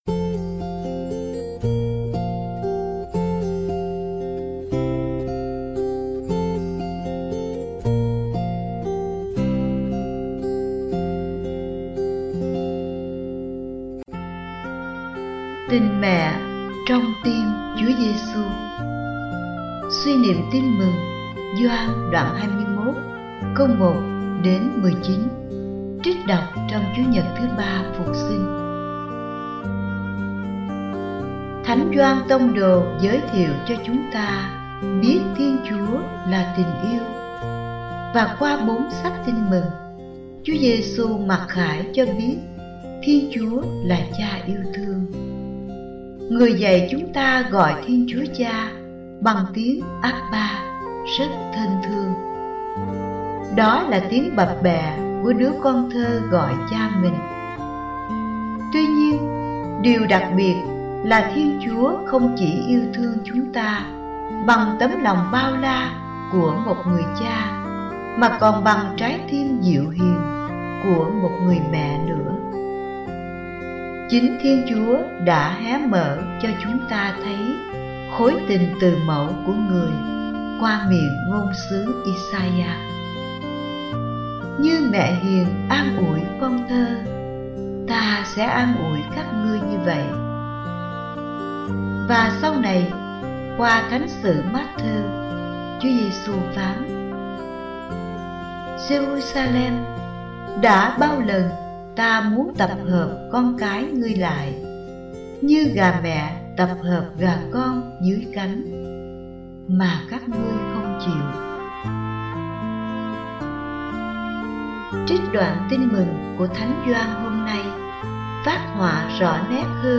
(Suy niệm Tin Mừng Gioan (Ga 21, 1-19) trích đọc trong Chúa Nhật 3 Phục Sinh)